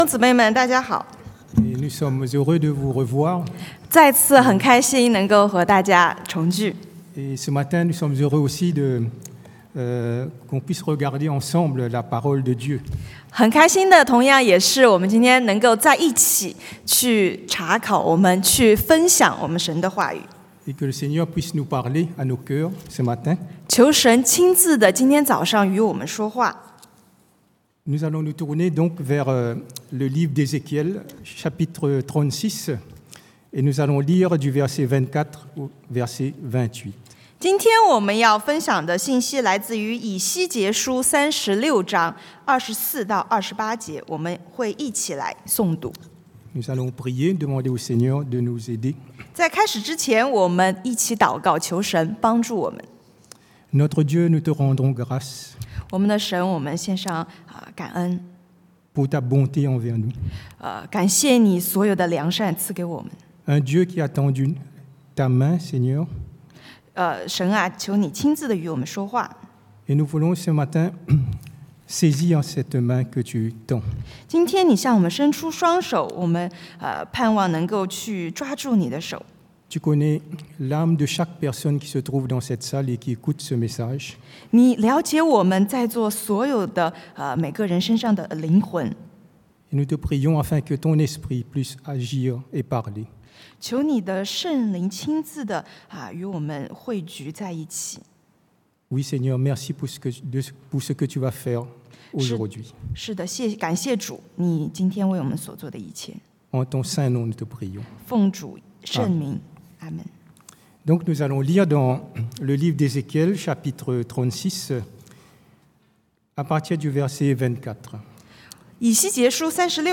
Predication du dimanche